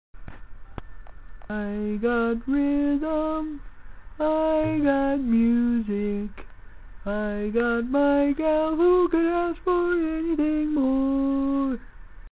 Key written in: D♭ Major
Each recording below is single part only.